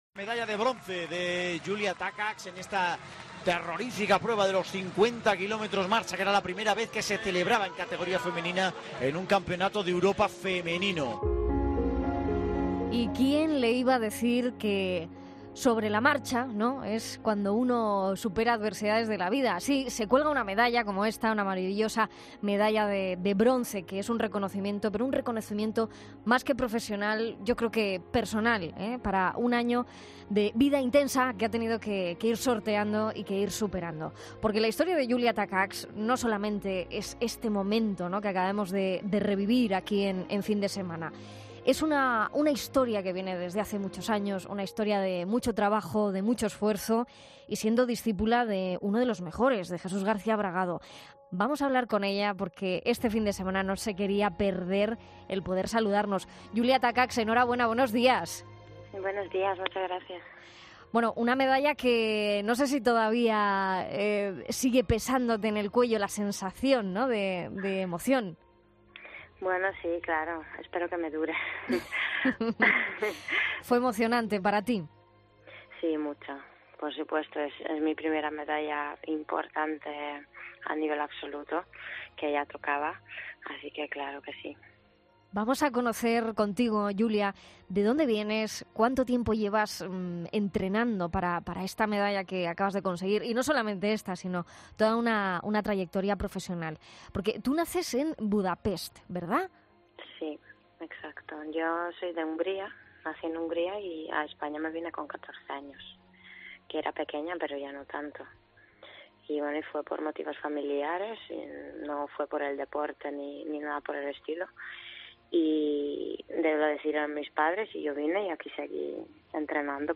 Escucha aquí la entrevista a Takacs